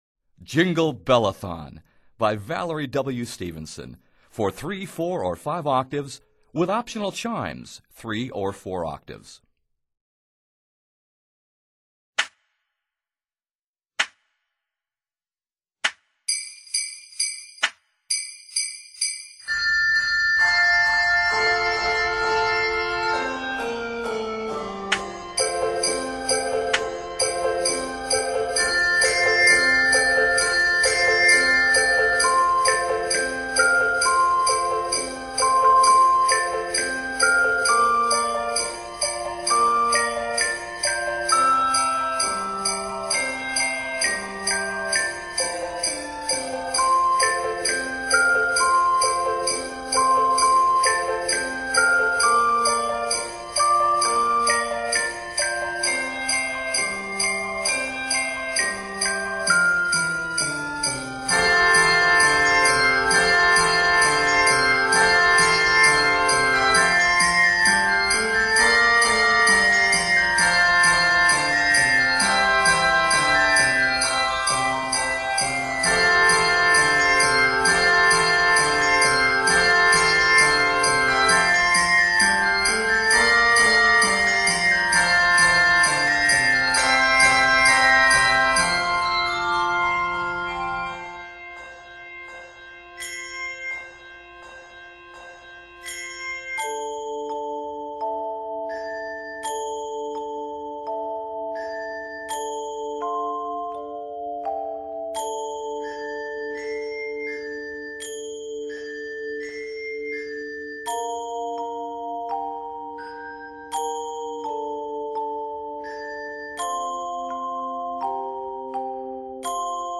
is arranged in F Major.